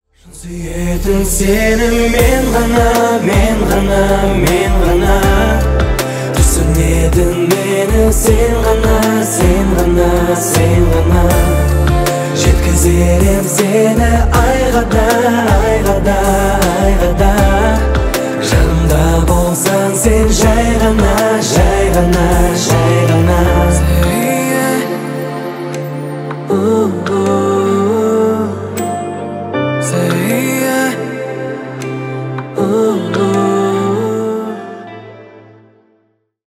• Качество: 320 kbps, Stereo
Поп Музыка
тихие
спокойные